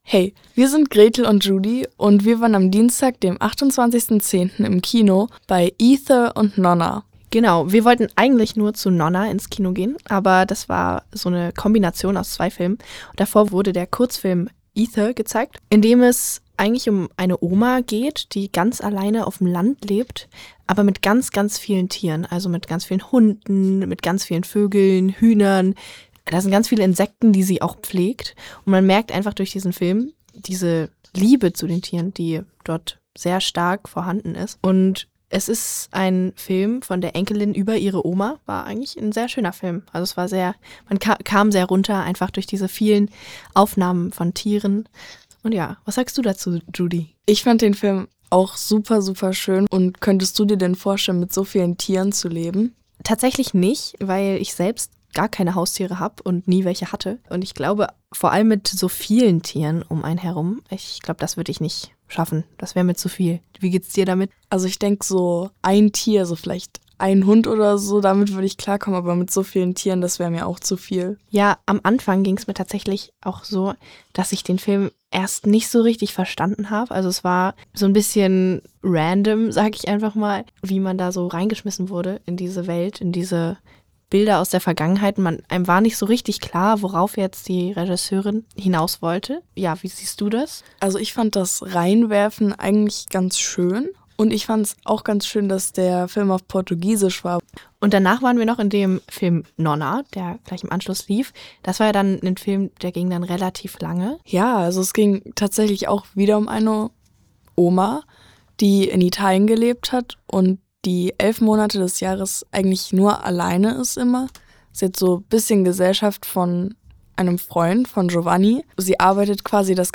Nonna-Gespraech.mp3